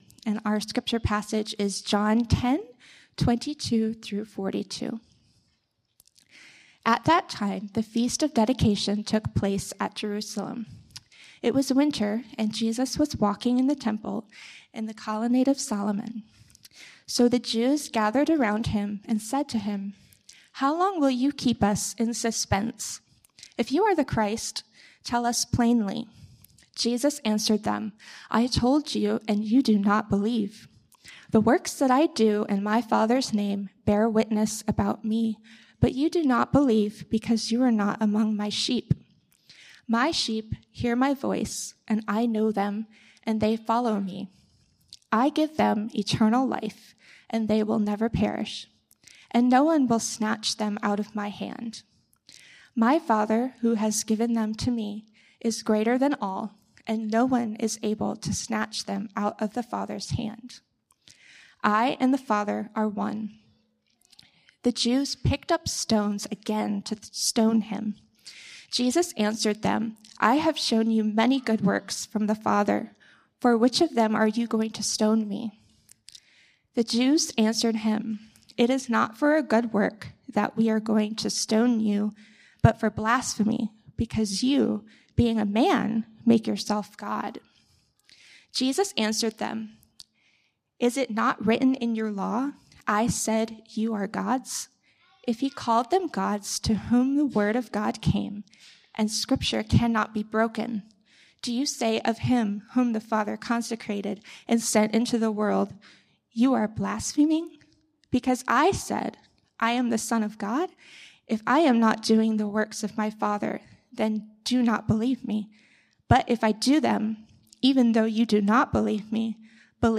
Weekly sermons from Redeemer City Church in Madison, Wisconsin, which seeks to renew our city through the gospel.